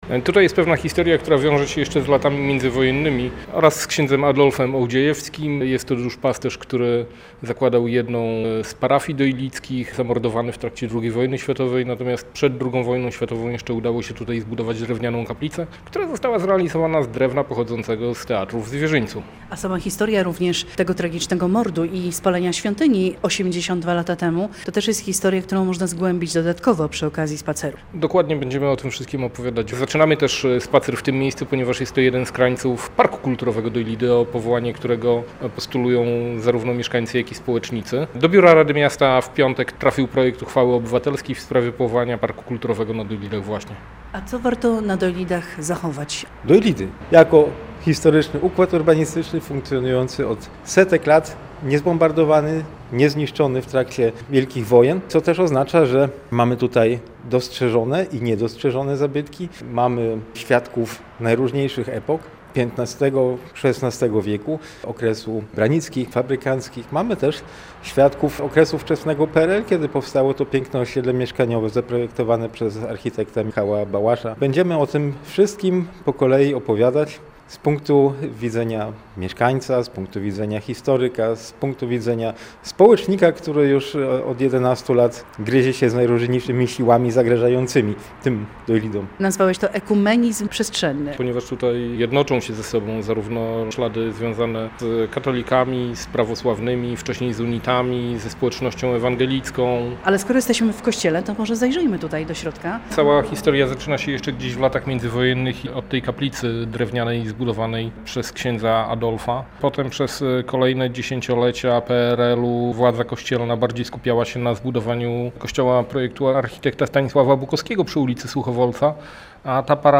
Miejsce – to plac przed Kościołem pw. Chrystusa Króla.